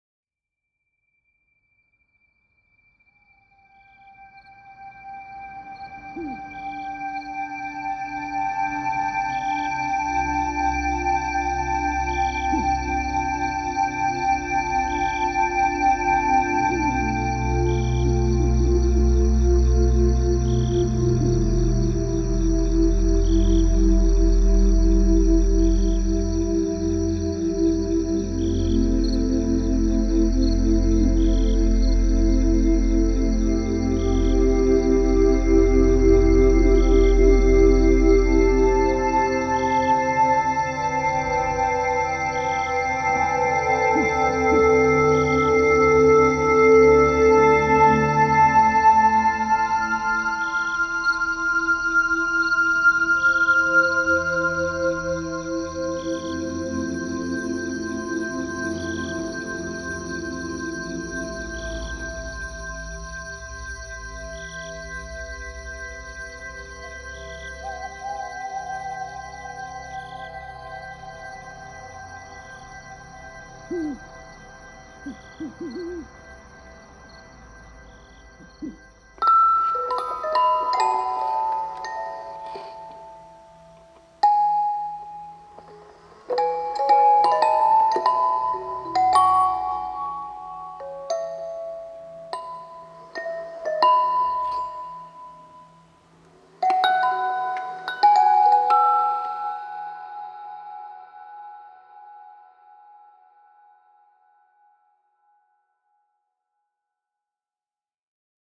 Genre : Score